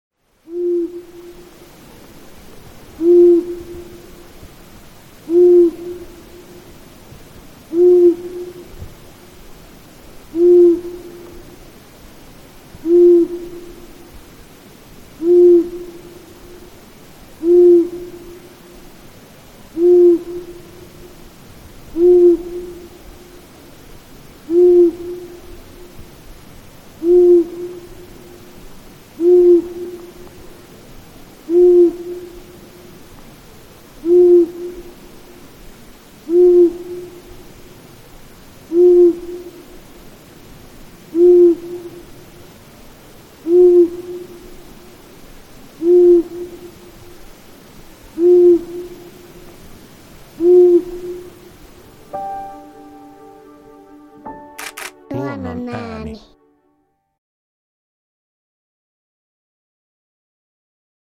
Kuuntele: Sarvipöllö
Sarvipöllön soidinääni hyvin matala ”huu, huu…”, joka toistuu kolmen sekunnin välein.
Naaras vastailee määkivällä ”meääh”-äänellä. Pesän ja poikueen luona emot varoittelevat haukkuvasti ”kvuek-kvuek…”.